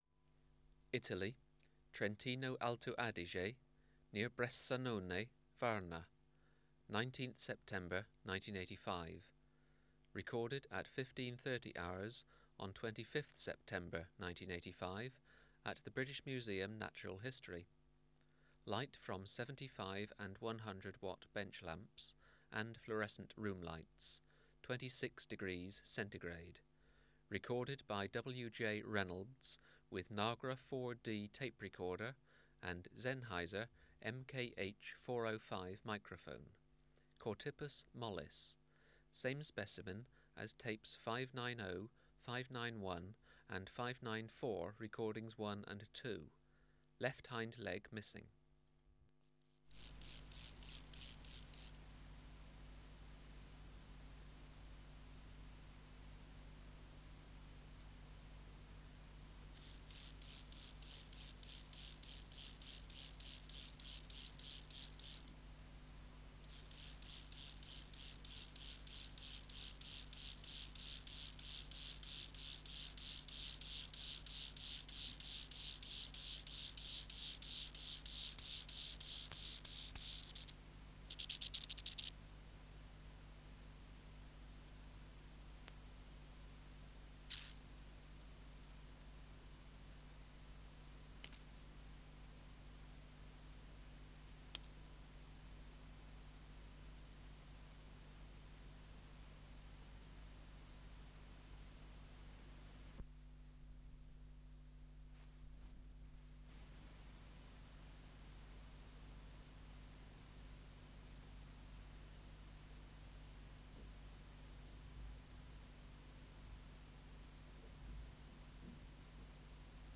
Recording Location: BMNH Acoustic Laboratory
Reference Signal: 1 kHz for 10 s
Substrate/Cage: Small recording cage
Courtship: female 20 cm from male, but after noises with last few songs
Microphone & Power Supply: Sennheiser MKH 405 Distance from Subject (cm): 7 Filter: Low Pass, 24 dB per octave, corner frequency 20 kHz